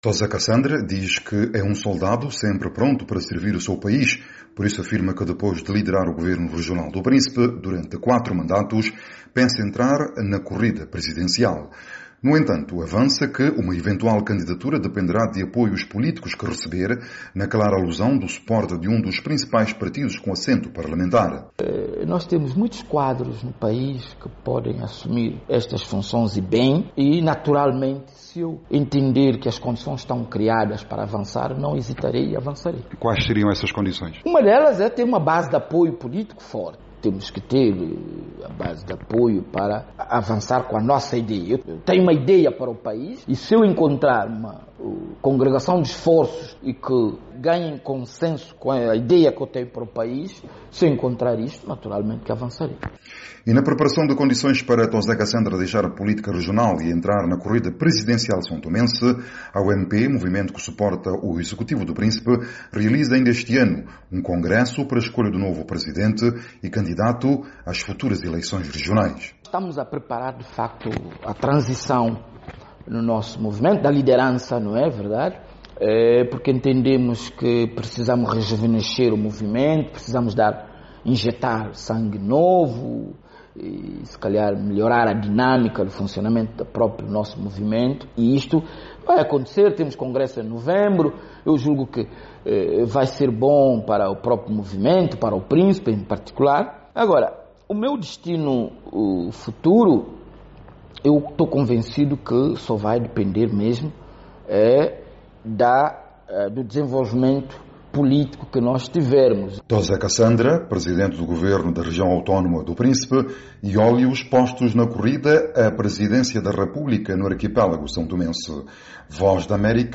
Em entrevista exclusiva à VOA, Cassandra deixa expressa a sua intenção de concorrer as próximas eleições para a presidência da Republica em São Tome e Príncipe.